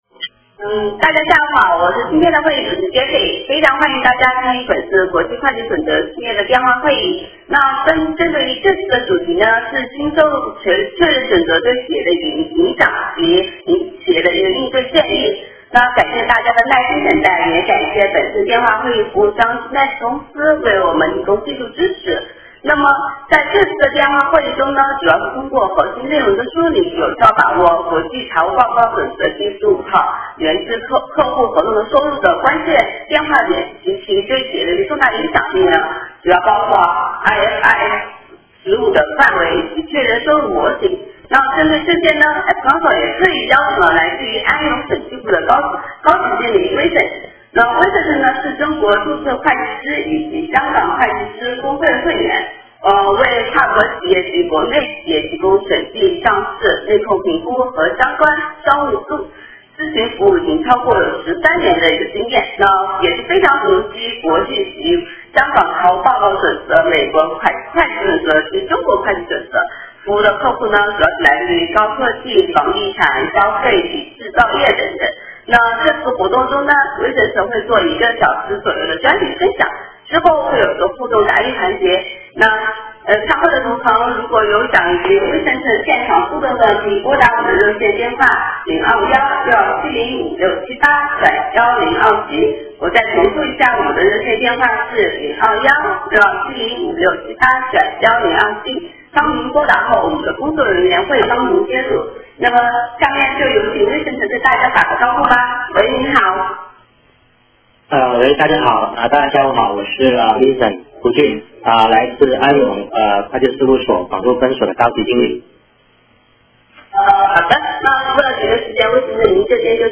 电话会议
互动答疑